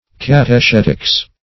Search Result for " catechetics" : The Collaborative International Dictionary of English v.0.48: Catechetics \Cat`e*chet"ics\, n. The science or practice of instructing by questions and answers.